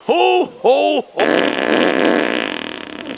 Ho Ho Ho...  Hahaha.
santa-fart.wav